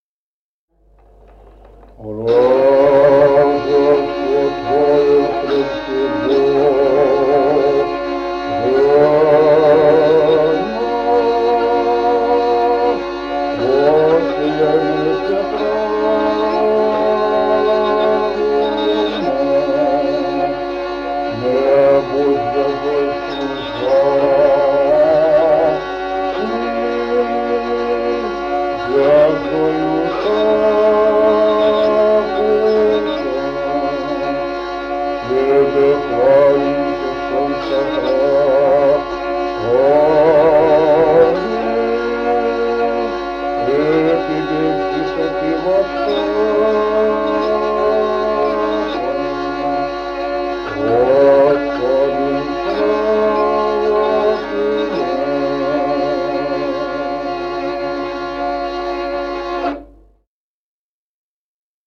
Музыкальный фольклор села Мишковка «Рождество Твое», тропарь Рождеству Христову, глас 4-й. И 0102-03.